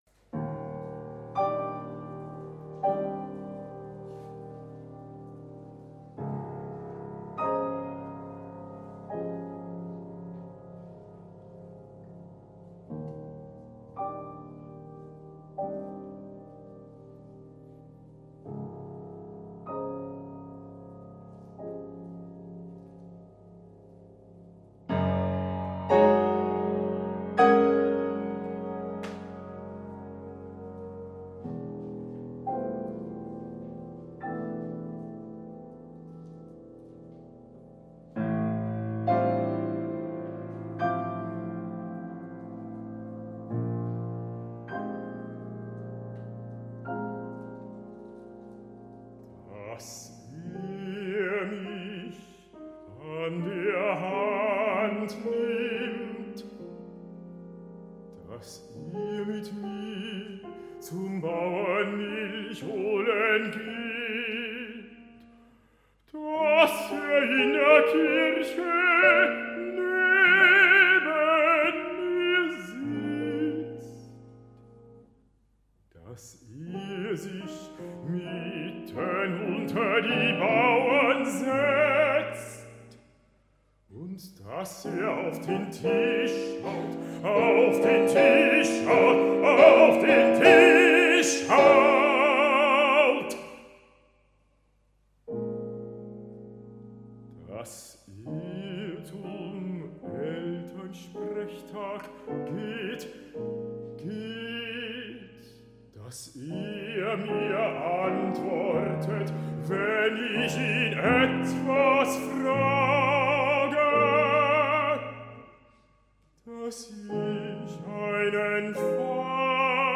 für Bariton und Klavier